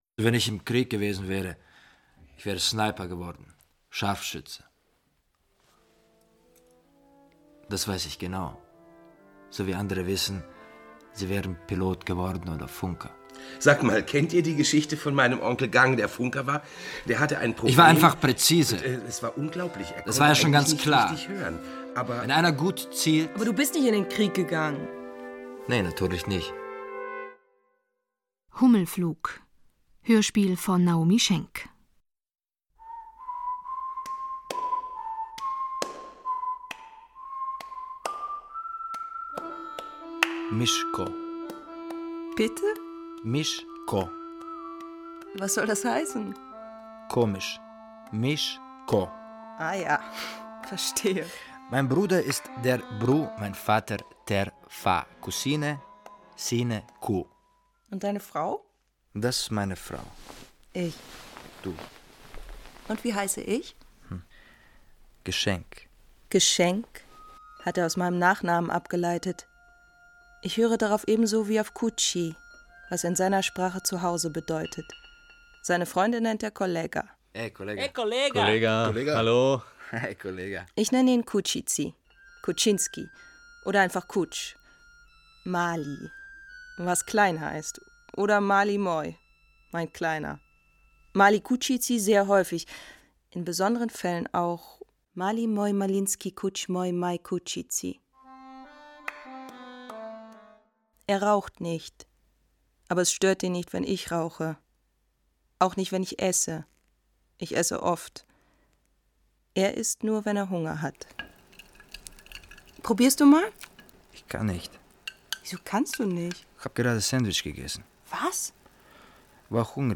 Literarische Hörspiele